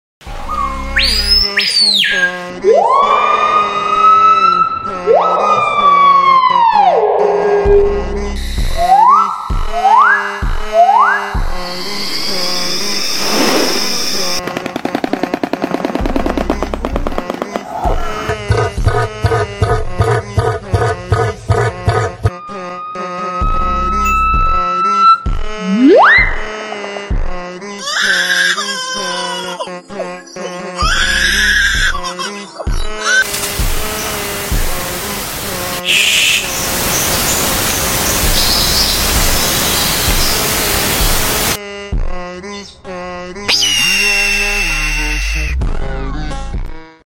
The Best Scary Bird Sound sound effects free download